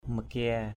/mə-kia:/ makia mk`% [Cam M] (d.) cây thị = Diospyros decandra.